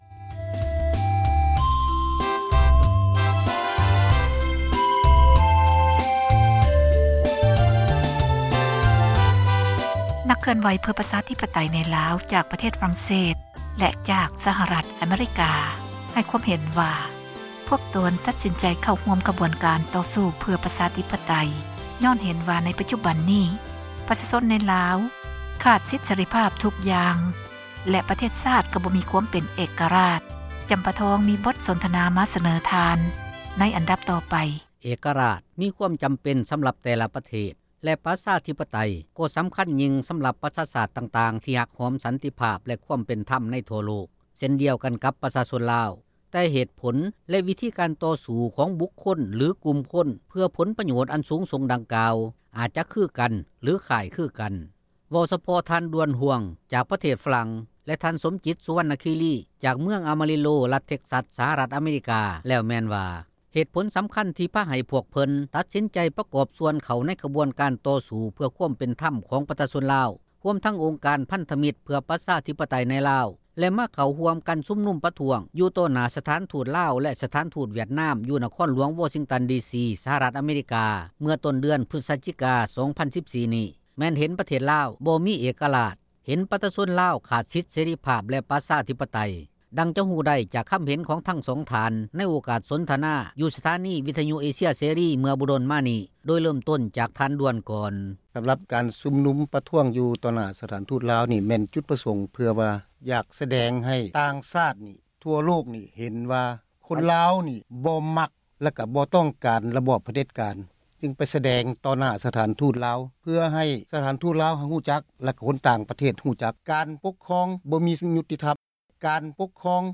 ສໍາພາດນັກເຄື່ອນໄຫວເພື່ອສິດ ເສຣີ ໃນລາວ